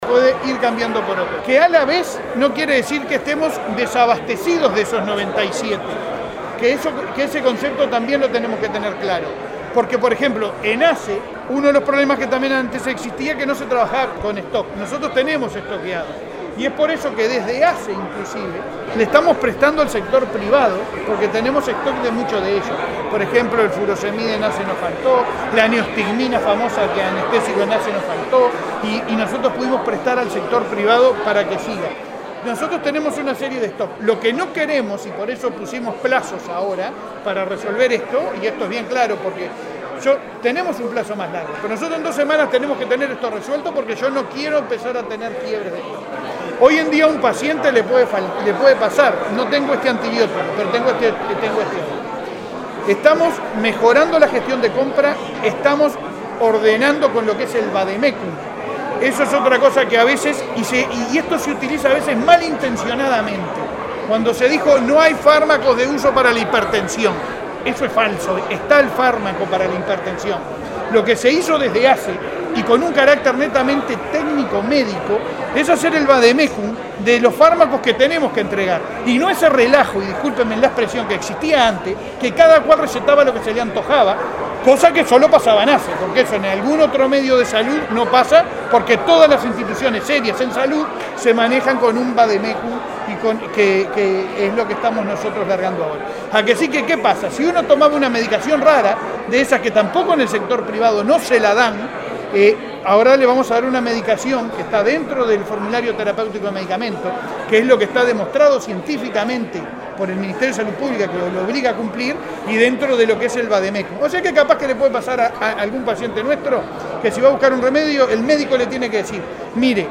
El secretario de Presidencia, Álvaro Delgado, y el presidente de ASSE, Leonardo Cipriani, destacaron en la ceremonia el trabajo en conjunto entre el gobierno nacional y departamental para atender la demanda de los usuarios de las dos policlínicas cercanas.